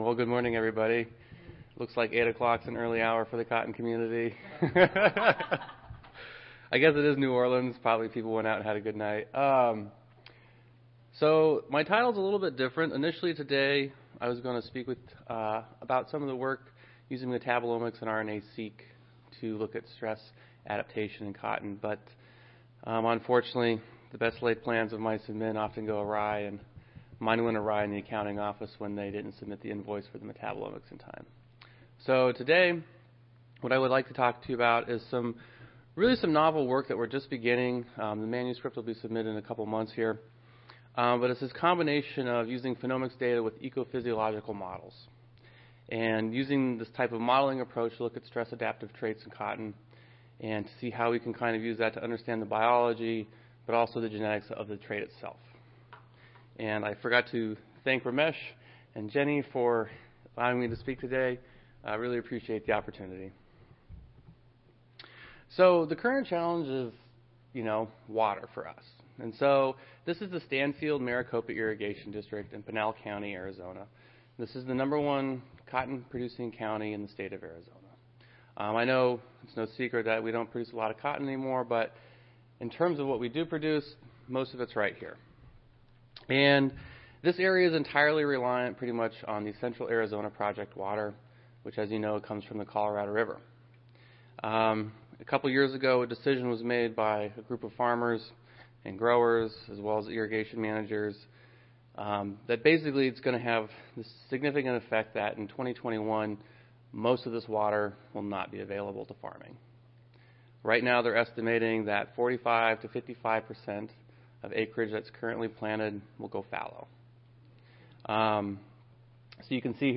Genomics Plenary Session
Recorded Presentation